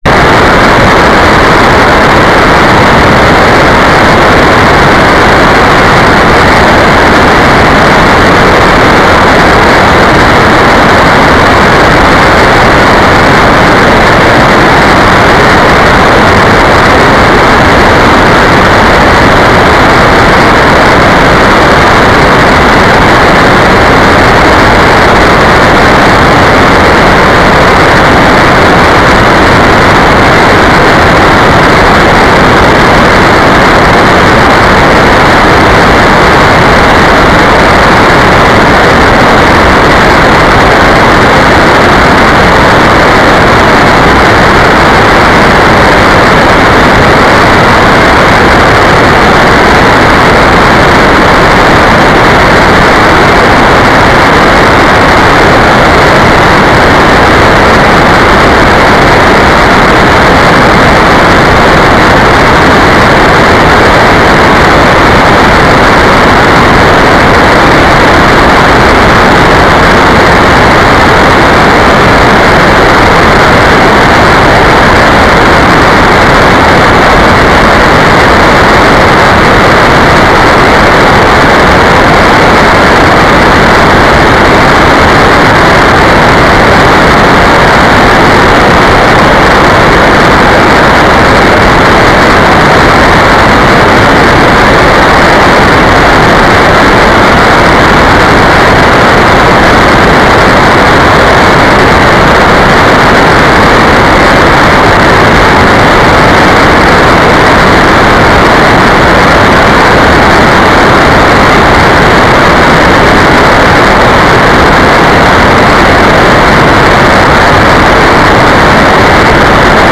"waterfall_status": "without-signal",
"transmitter_description": "Mode U - FSK2k4",